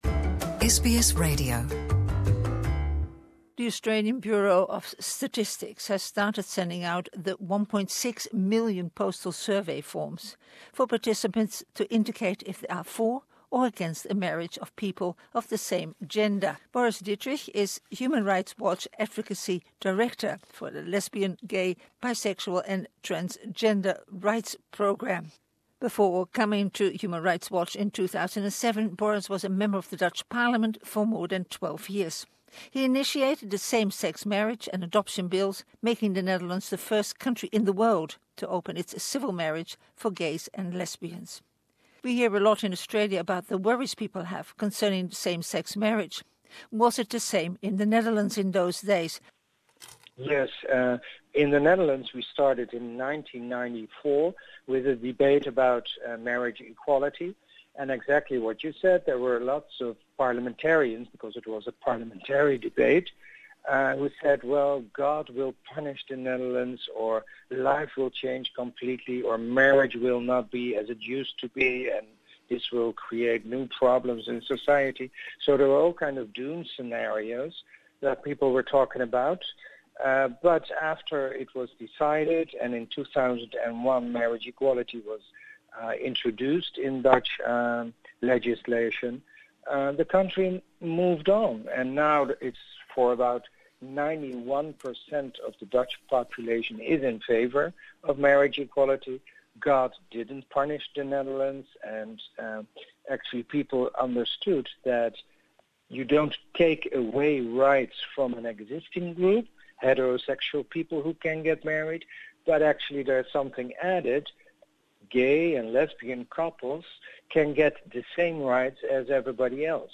Interview in English: As Australia decides which way to vote on same-sex marriage, we talk to Boris Dittrich who heads the Gay, Bisexual and Transgender Rights Program of the the UN.